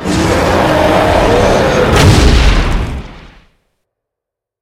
die.ogg